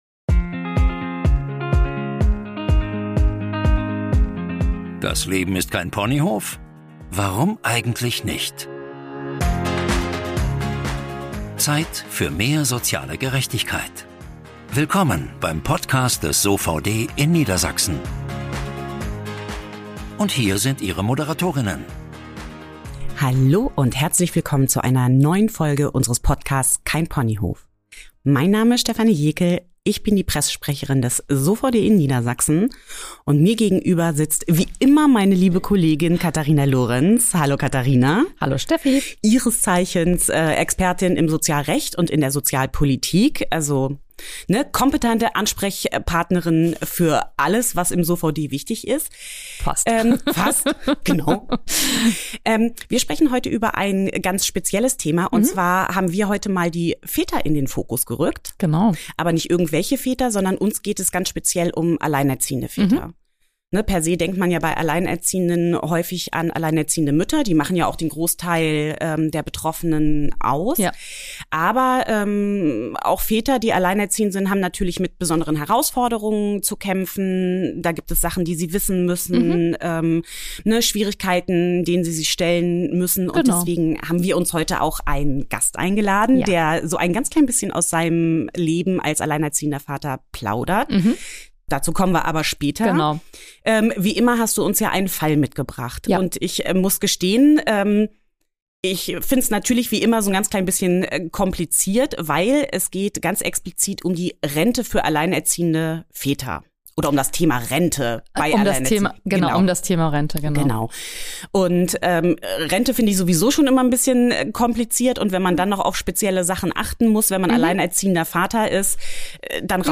Danach hören Sie das inspirierende Gespräch